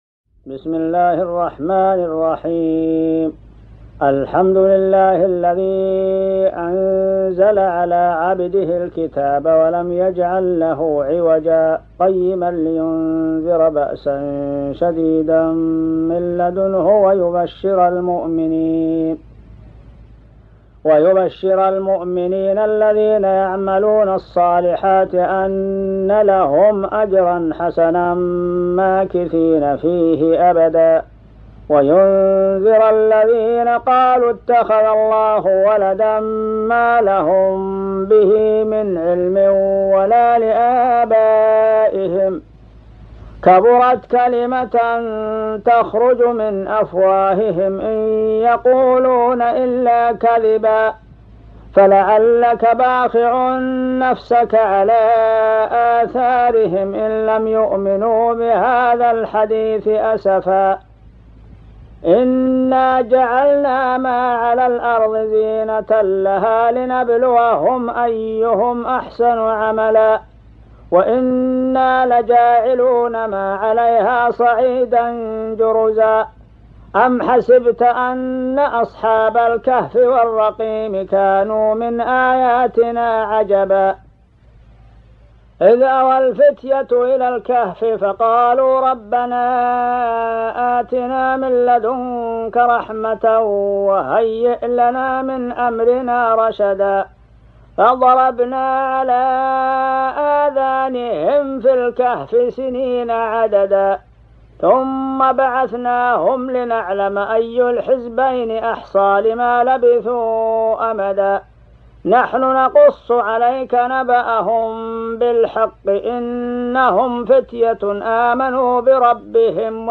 جديد - سورة الكهف من صلاتي التراويح والقيام بصوت ائمة الحرمين الشريفين القدامى والمعاصرين صوتي MP3 ومرئي بجودة عالية وغالية